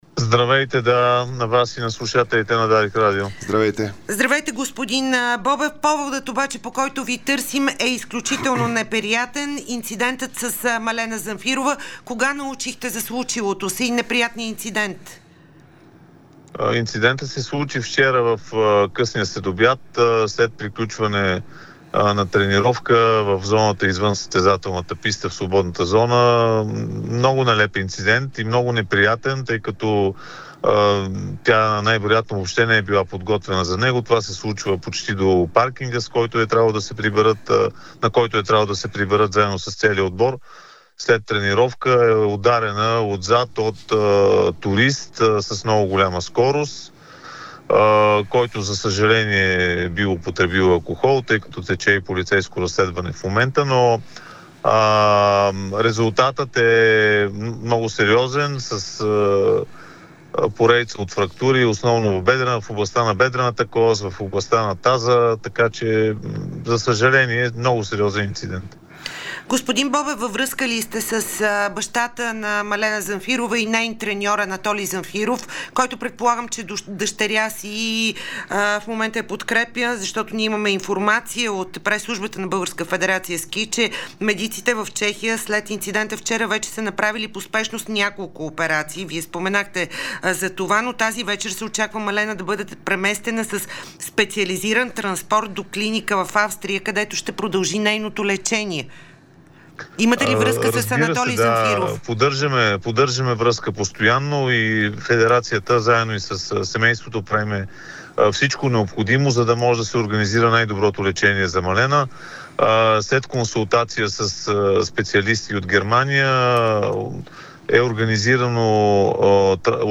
заяви ексклузивно пред Спортното шоу на Дарик и Dsport